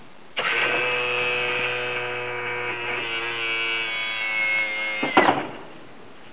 stereo to mono
gear-dn.wav